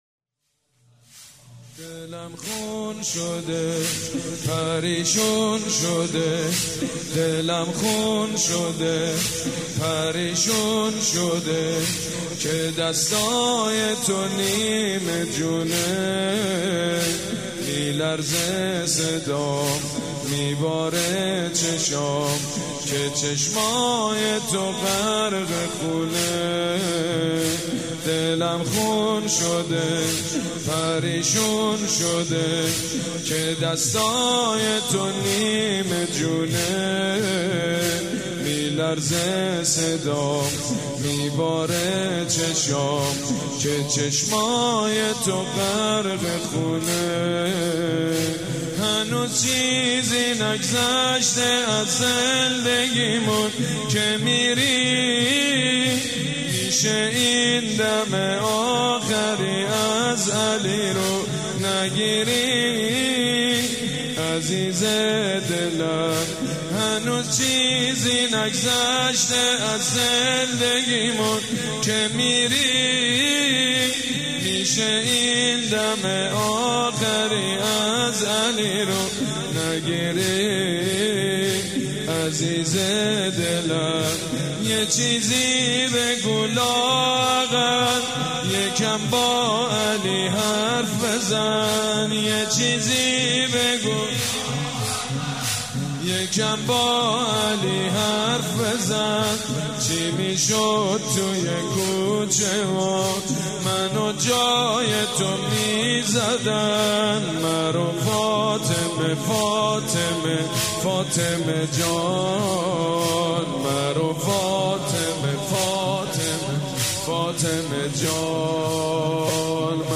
شب دوم فاطميه دوم١٣٩٤
مداح
مراسم عزاداری شب دوم